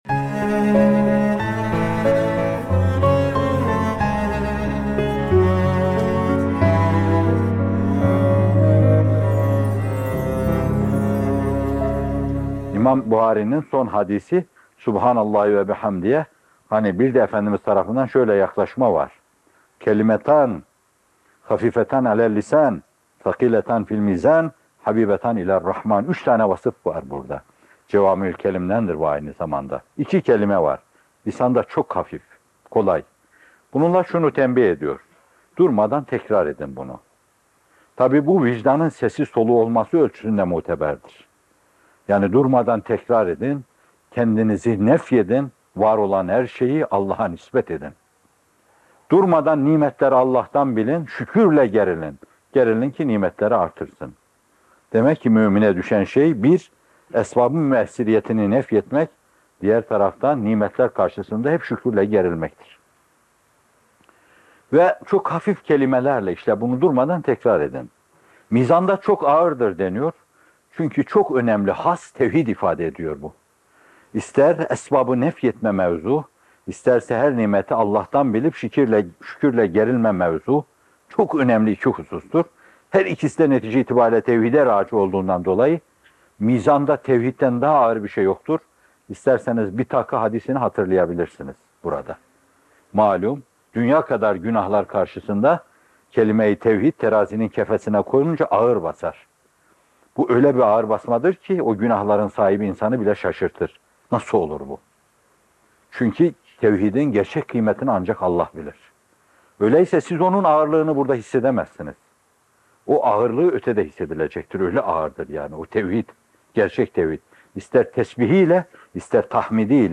Bir Tesbih: Lisanda Hafif, Mizanda Ağır, Rahman’a Sevimli - Fethullah Gülen Hocaefendi'nin Sohbetleri